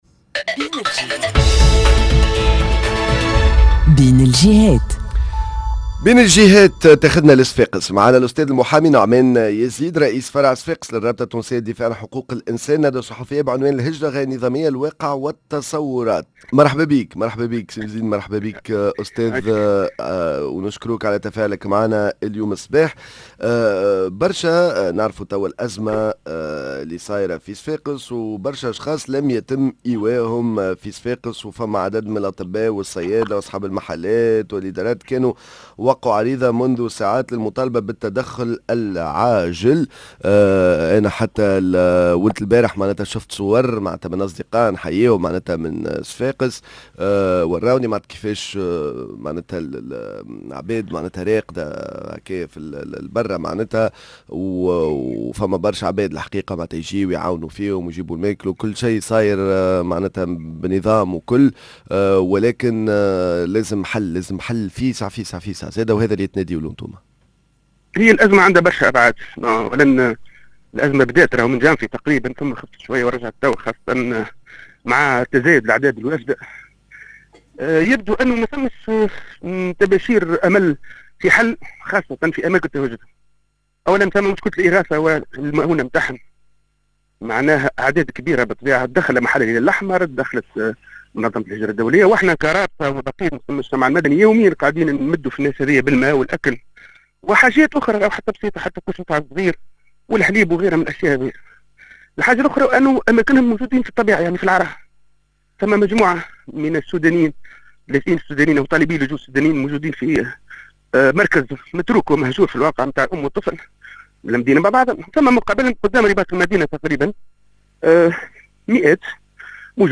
بين الجهات صفاقس: ندوة صحفية بعنوان الهجرة غير النظامية: الواقع والتصورات.